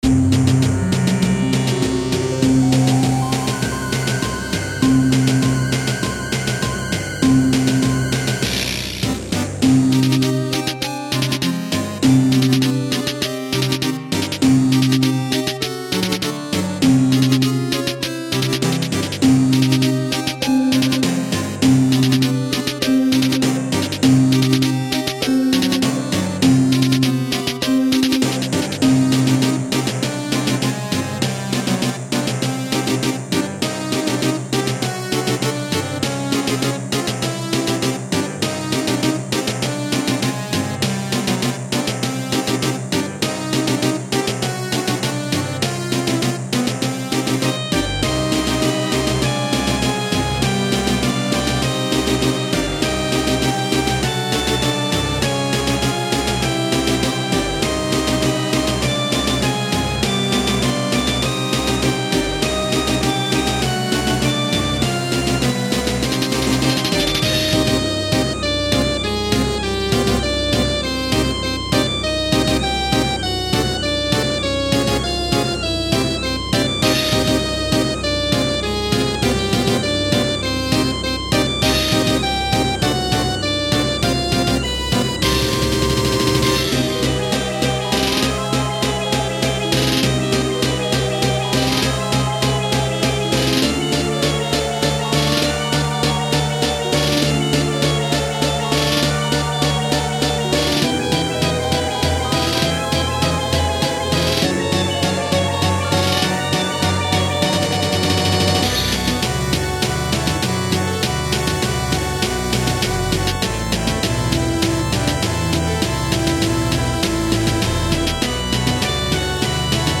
Filed under: Audio / Music, Remix, Video Game Music